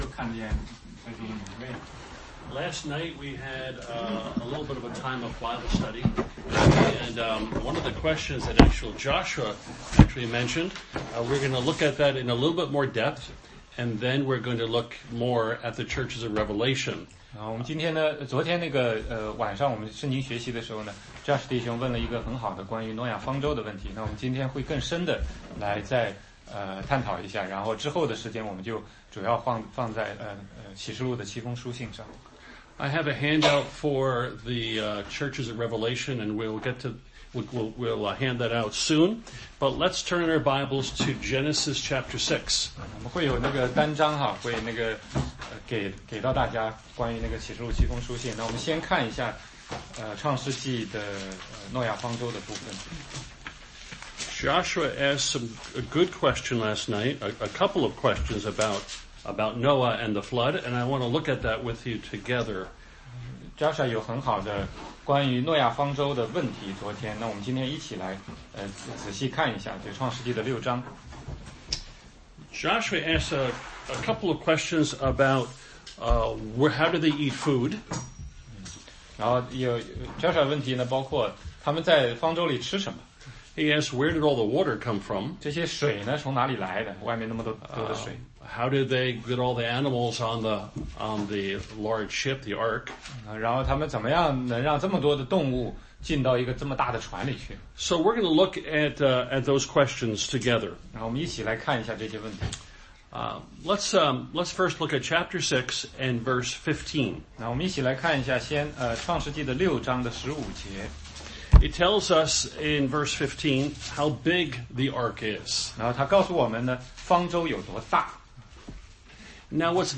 16街讲道录音 - 启示录七封书信之士每拿教会